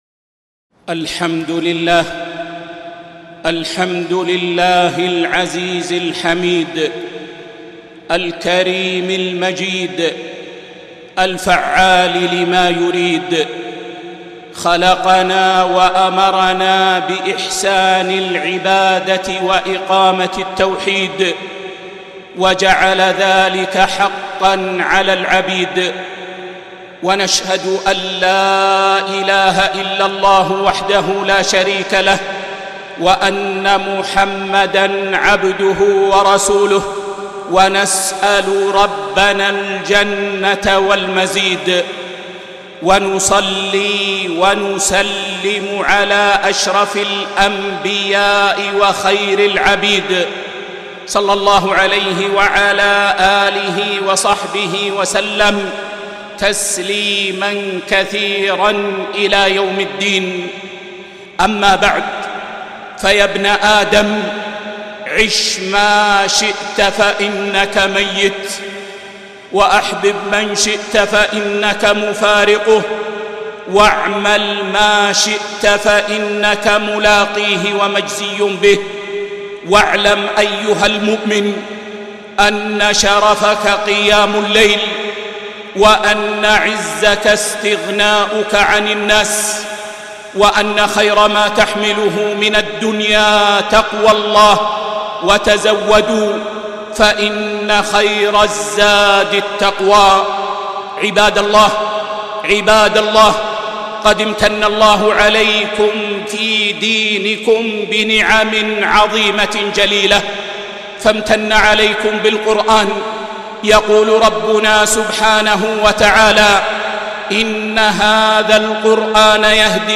خطبة - نعم الله في الدين ٦-٣-١٤٤٢ في مسجد قباء